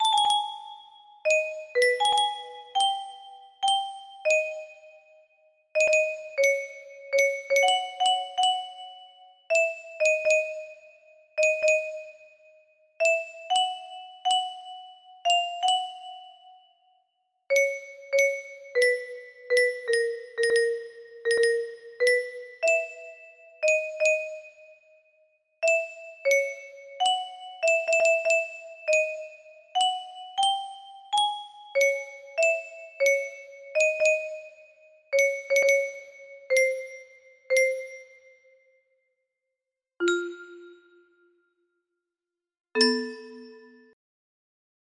ave maria pa music box melody